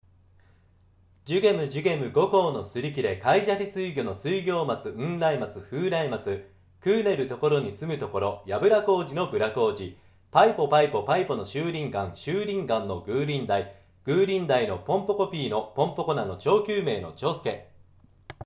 15秒（テキパキバージョン）
普段の話し方にも適した速さです。